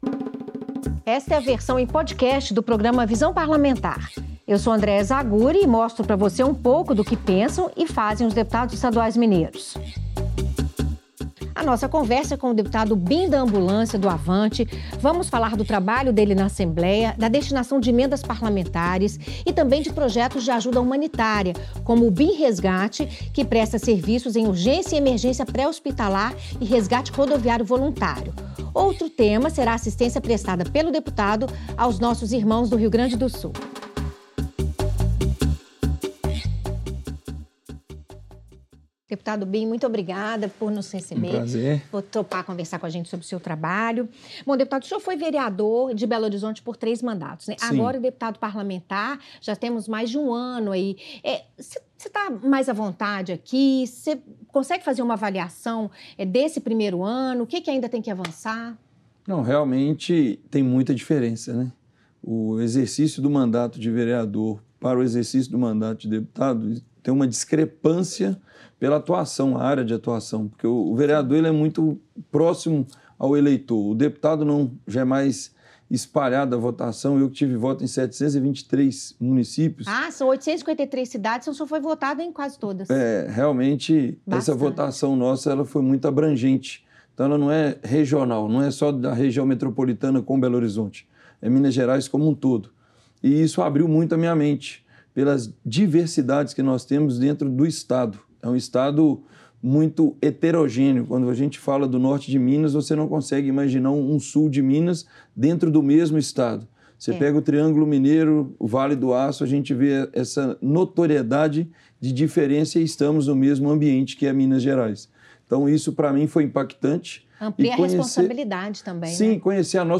O deputado fala sobre o projeto social que fundou em 2008 para prestar socorro voluntário a vítimas de acidentes. O parlamentar também explica como foram as peregrinações que fez de Belo Horizonte ao Santuário de Aparecida, no interior de São Paulo. Bim da Ambulância fala ainda sobre a viagem de helicóptero ao Rio Grande do Sul para prestar apoio às vítimas das enchentes no Estado, sobre a importância da valorização do ensino profissionalizante e sobre o Projeto de Lei 1273/2023, de autoria do deputado, que busca reconhecer as manobras de motocicletas como prática esportiva em Minas.